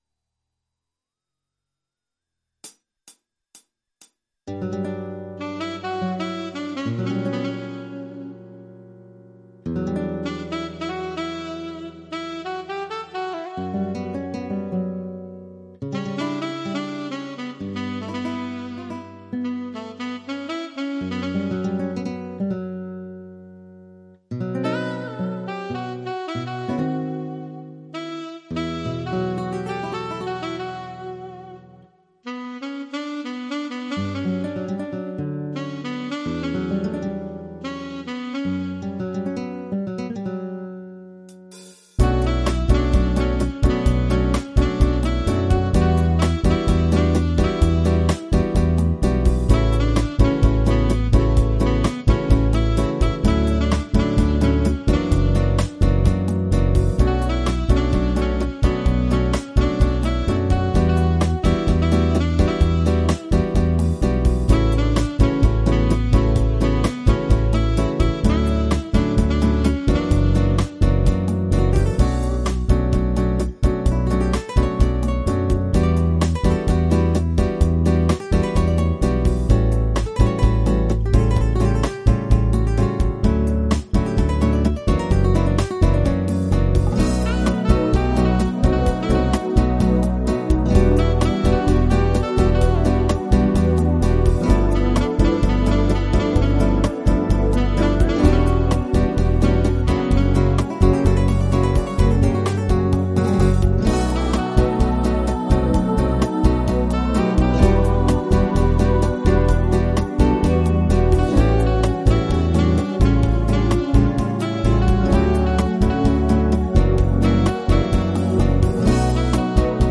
PRO INSTRUMENTAL VERSION